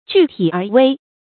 具體而微 注音： ㄐㄨˋ ㄊㄧˇ ㄦˊ ㄨㄟ 讀音讀法： 意思解釋： 具體：大體具備；微：微小。